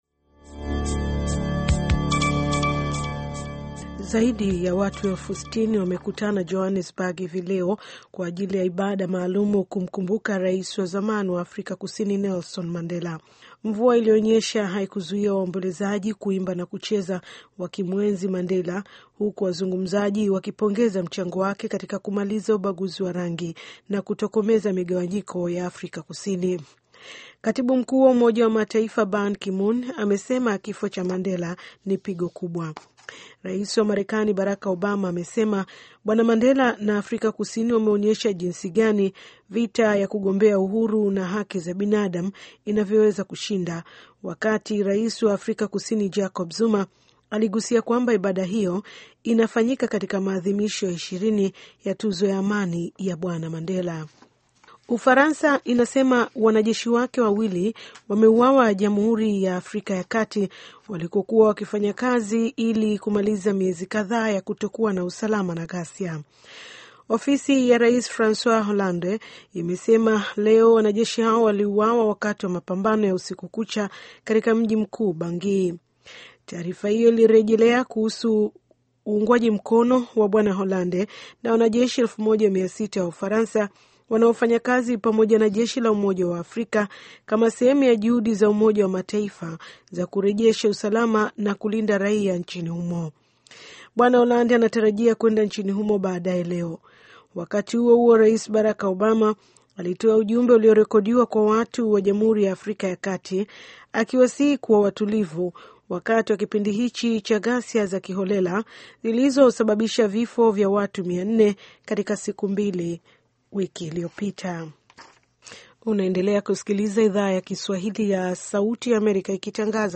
Taarifa ya Habari VOA Swahili - 6:10